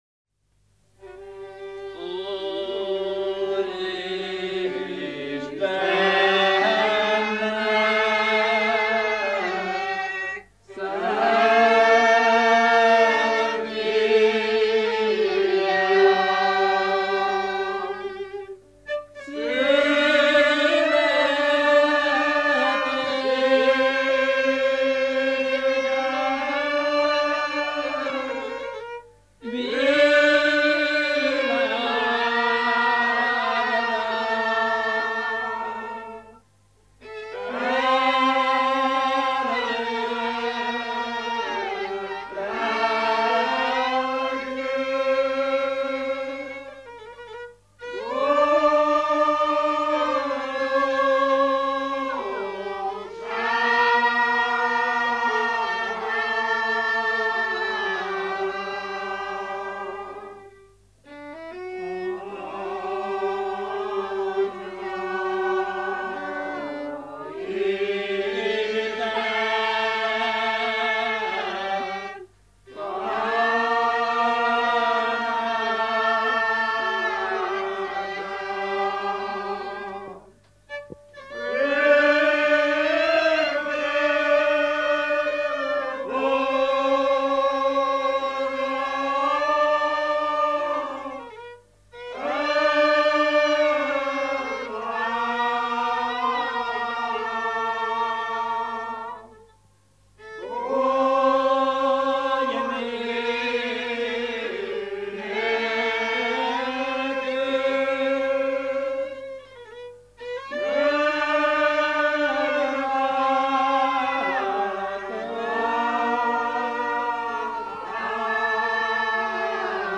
hegedű, ének, szövegmondás
karácsonyi ének
Magyarszovát
Mezőség (Erdély)